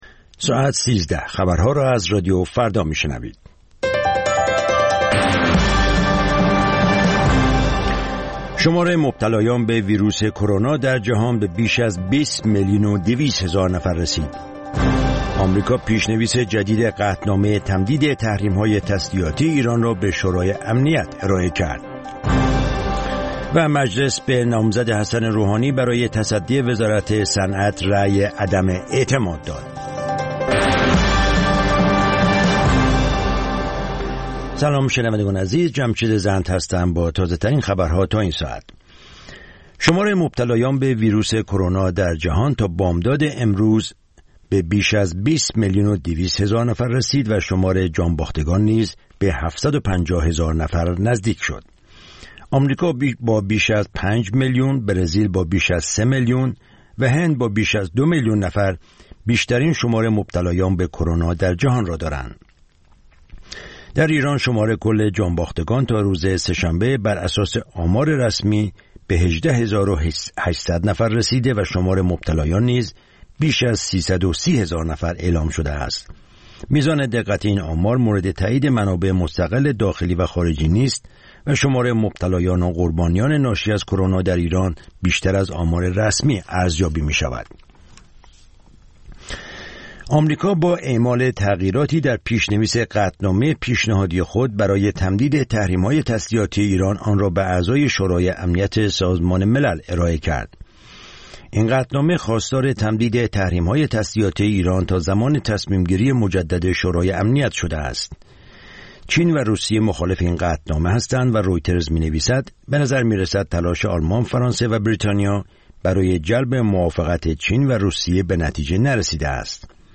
اخبار رادیو فردا، ساعت ۱۳:۰۰